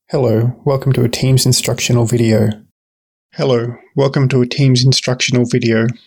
I used a compressor, filter curves (equaliser), limiter. I attempted to use the equaliser to bring out the bass and punch the highs a bit.